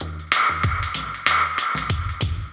Index of /musicradar/dub-designer-samples/95bpm/Beats
DD_BeatC_95-03.wav